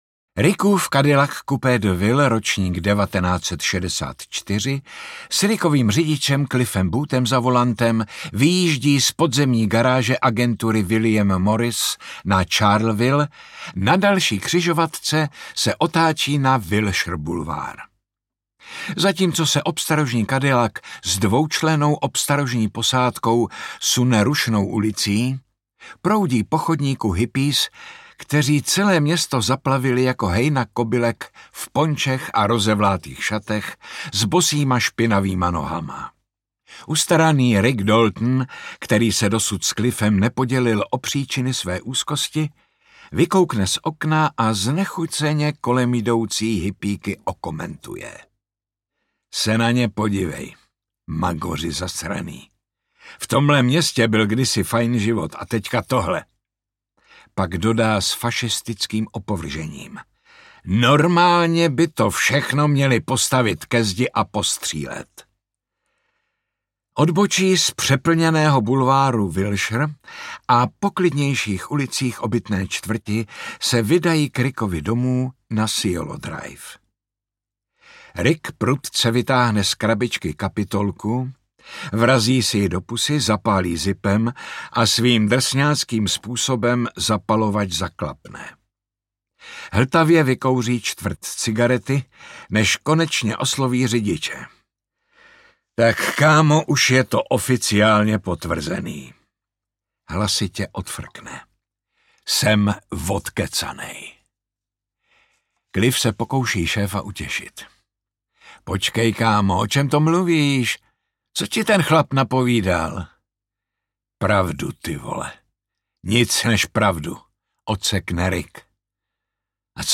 Tenkrát v Hollywoodu audiokniha
Ukázka z knihy